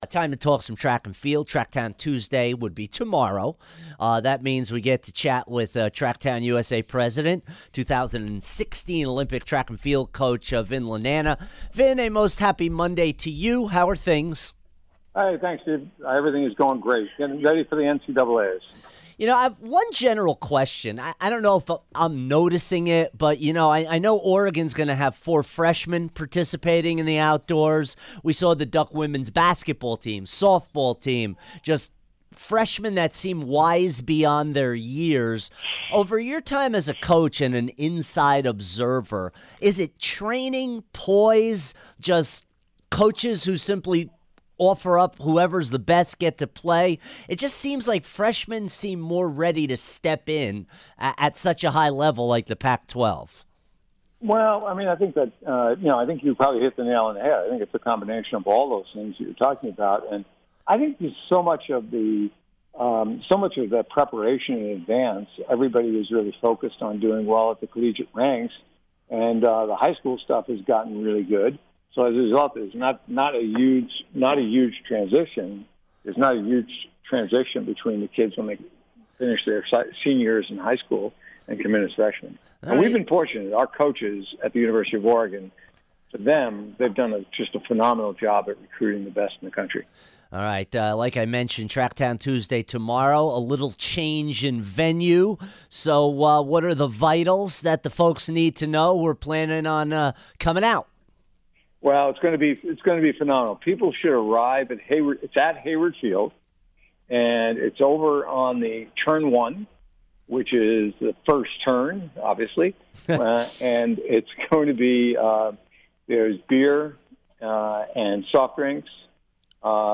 Vin Lananna Interview 6-5-17